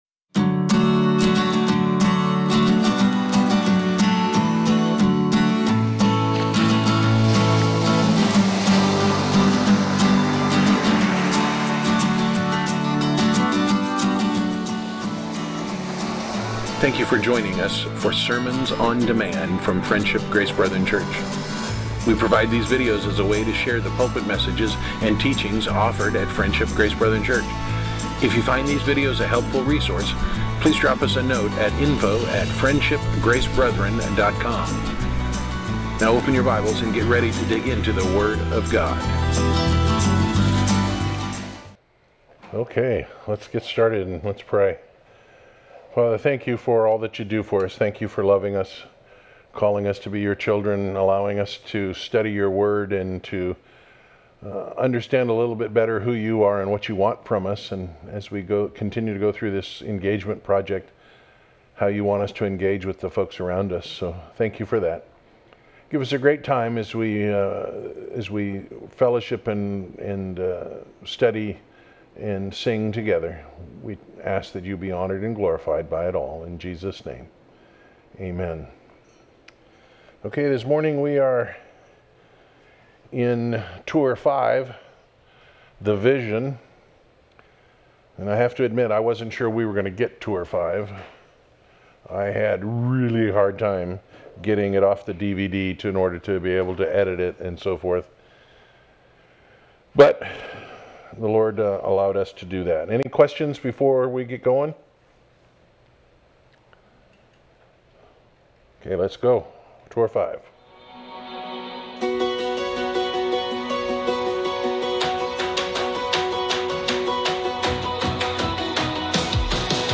Series: Engagement Project, Sunday School